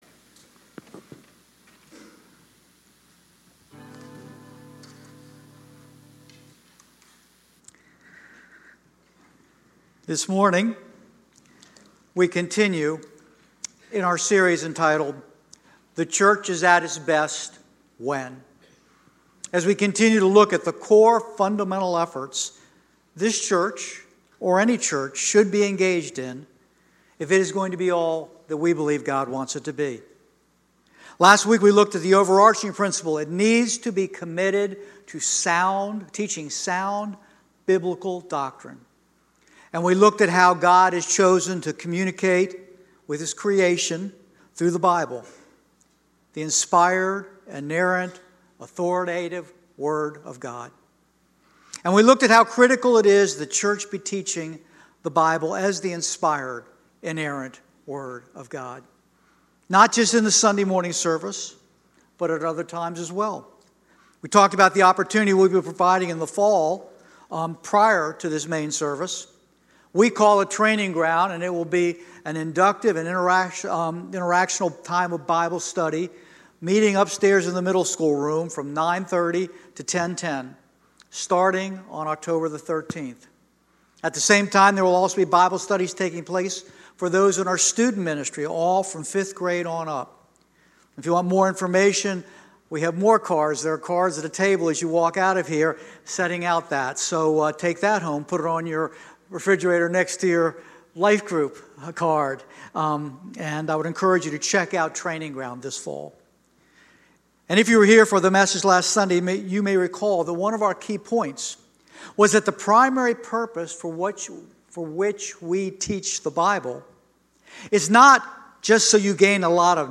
10:30 Service
Sermon